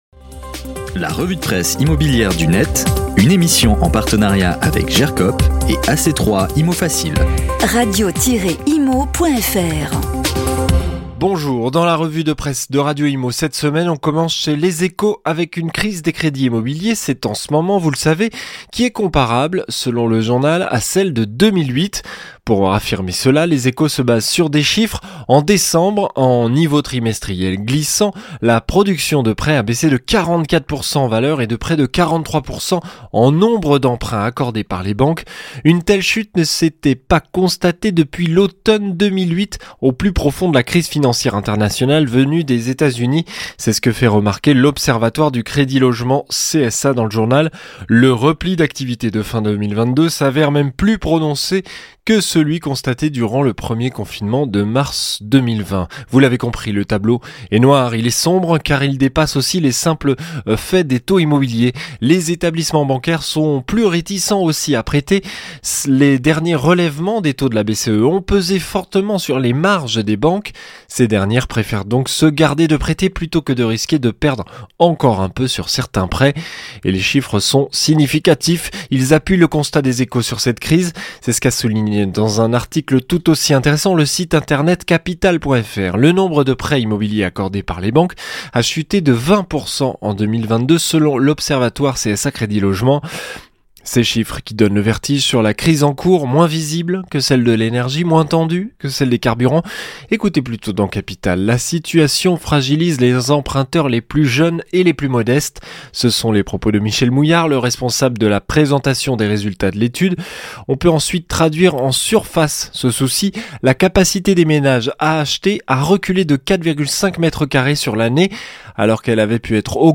Table Ronde 1 : Efficacité de la relation client, la sécurité des avis contrôlés (Part.3) - RENT 2018